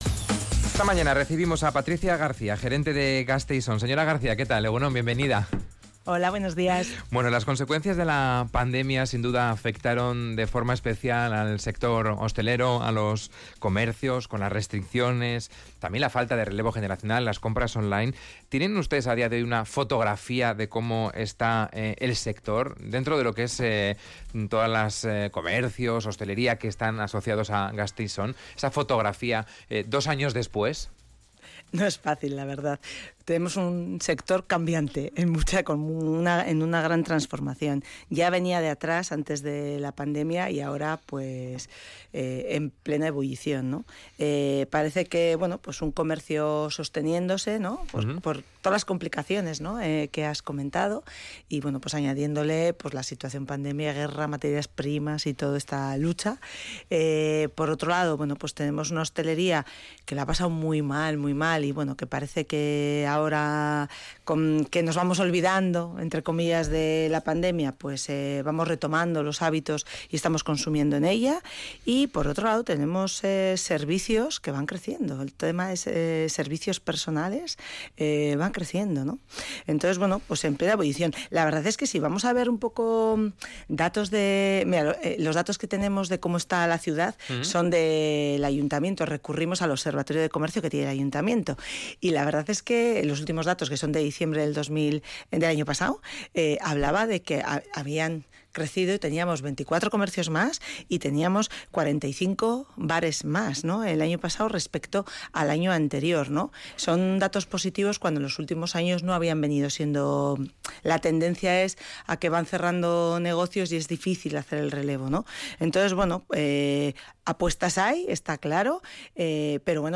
Radio Vitoria ENTREVISTA-DEL-DIA